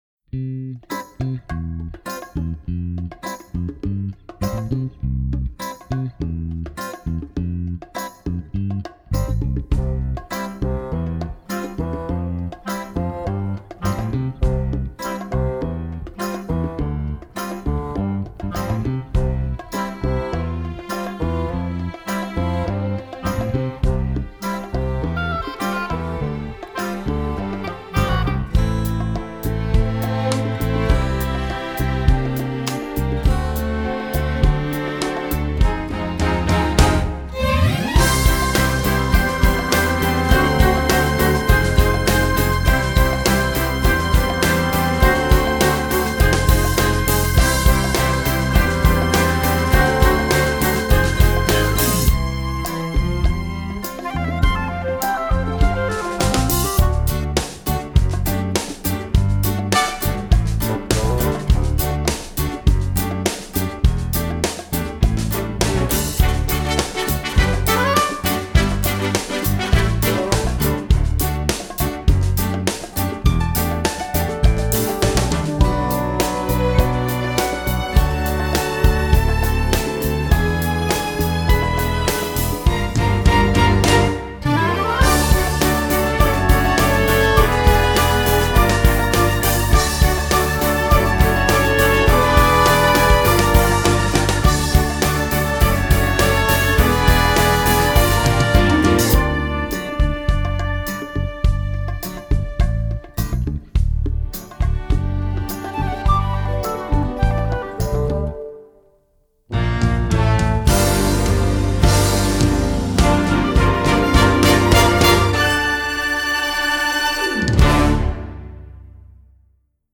Song with lyrics